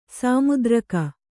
♪ sāmudraka